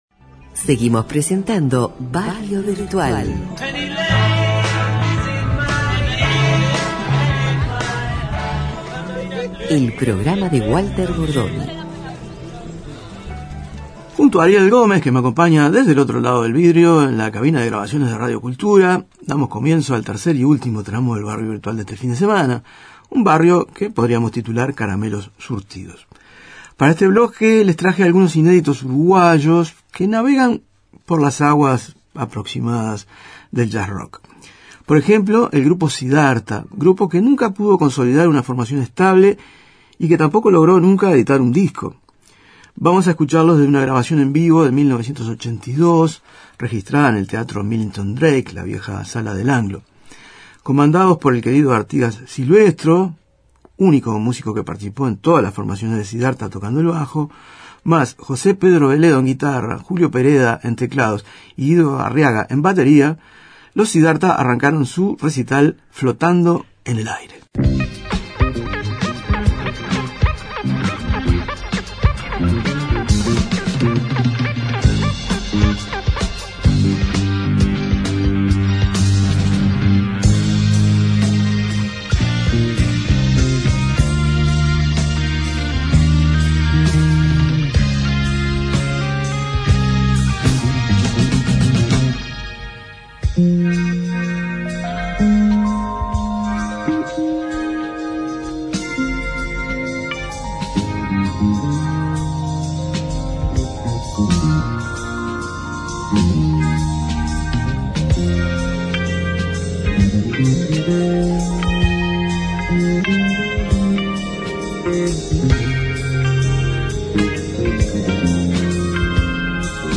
Este capítulo de Barrio Virtual tendremos una serie de músicas de muy diversos estilos y procedencias (más un prólogo de despedida al gran narrador Paul Auster)